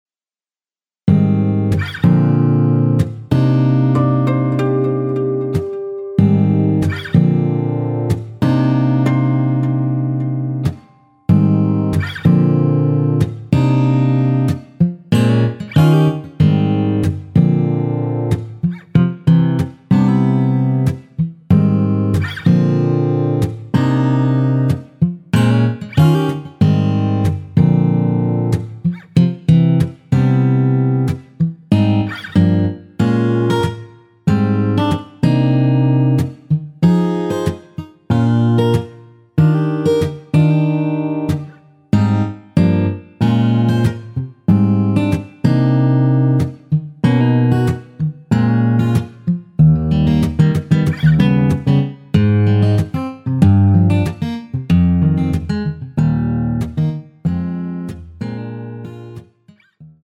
Cm
◈ 곡명 옆 (-1)은 반음 내림, (+1)은 반음 올림 입니다.
앞부분30초, 뒷부분30초씩 편집해서 올려 드리고 있습니다.
중간에 음이 끈어지고 다시 나오는 이유는